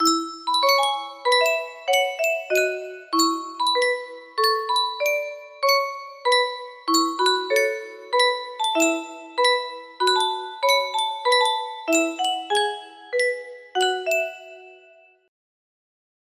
Yunsheng Music Box - TAYRRTOOT Y479 music box melody
Full range 60